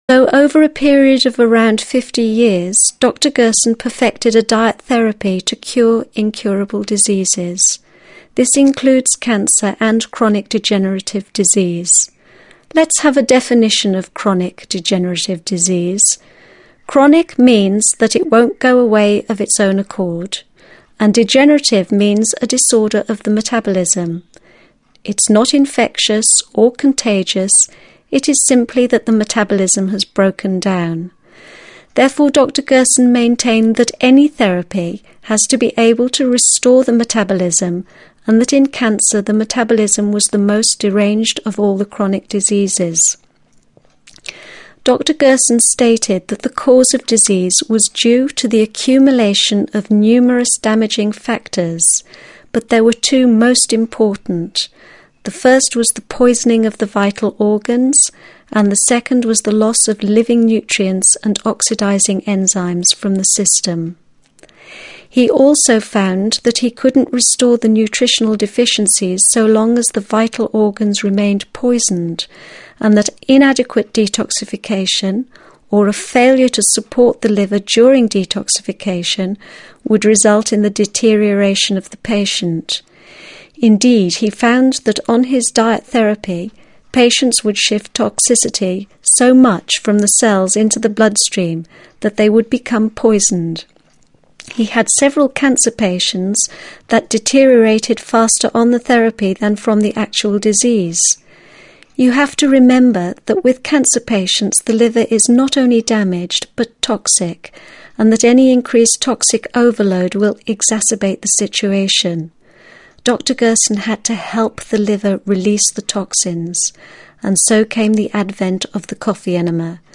It also contains a question and answer segment.